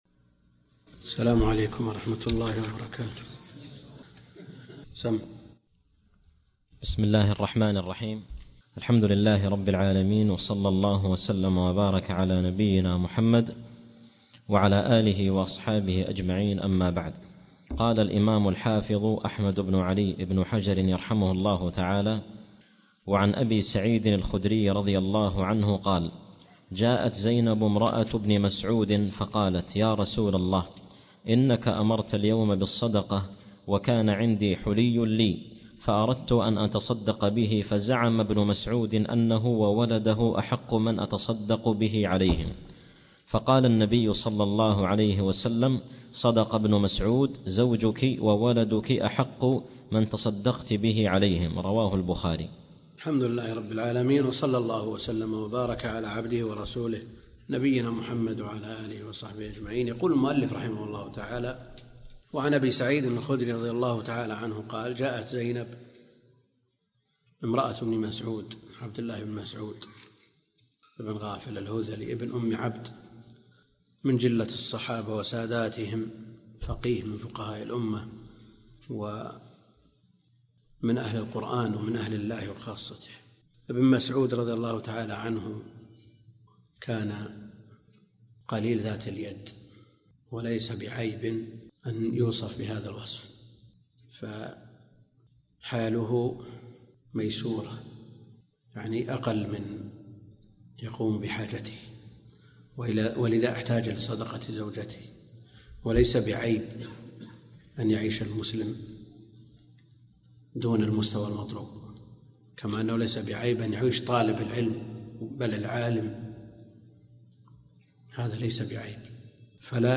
عنوان المادة الدرس (5) كتاب الزكاة من بلوغ المرام تاريخ التحميل الأثنين 29 يناير 2024 مـ حجم المادة 34.25 ميجا بايت عدد الزيارات 59 زيارة عدد مرات الحفظ 22 مرة إستماع المادة حفظ المادة اضف تعليقك أرسل لصديق